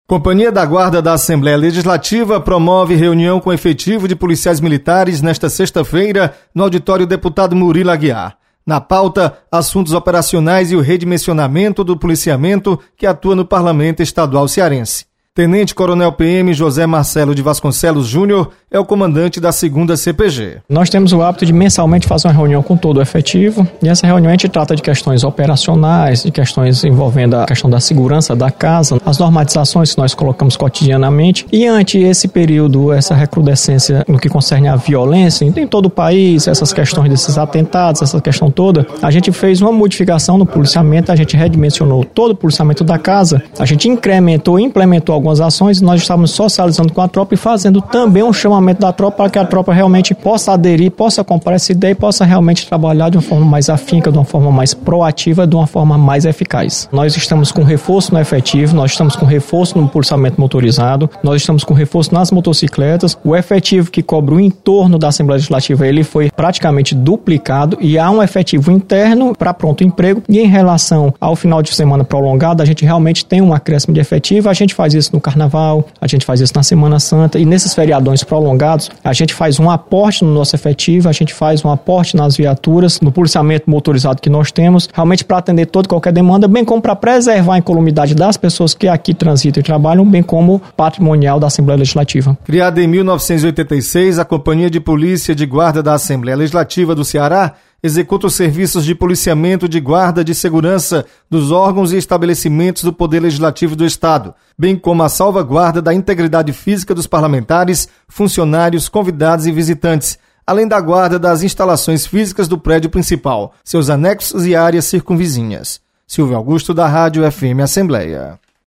Reunião da Companhia da Guarda da Assembleia. Repórter